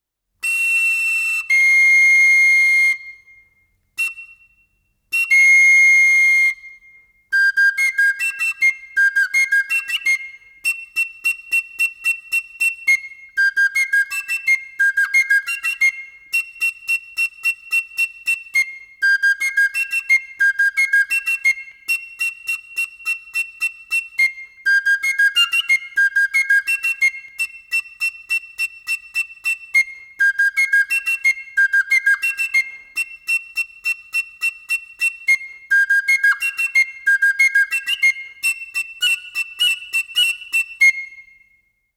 MARXA BASTONS